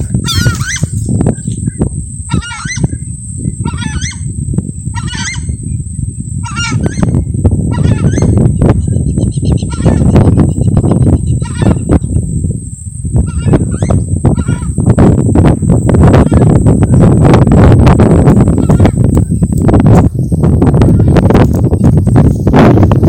Southern Screamer (Chauna torquata)
Location or protected area: Reserva Natural y Dique La Angostura
Condition: Wild
Certainty: Photographed, Recorded vocal